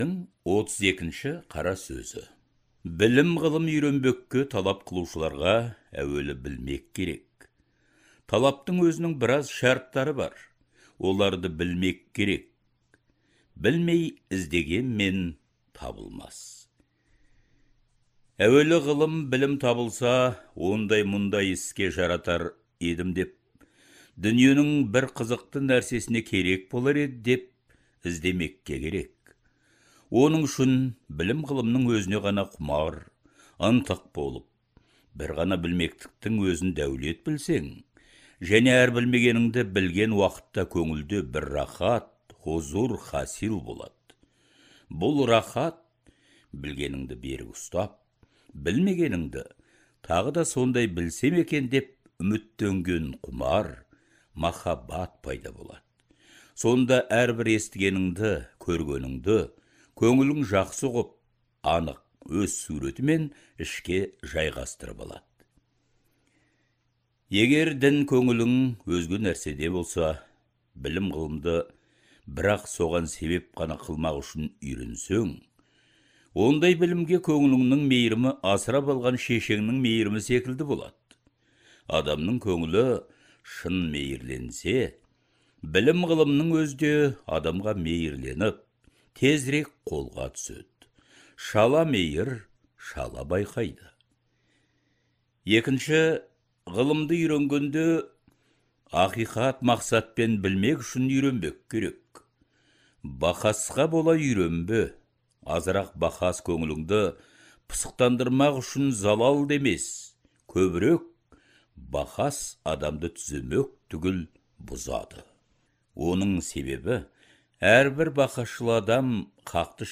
Даналық көсемсөздердің аудио нұсқасы Әдебиет институтының студиясында жазылып алынған.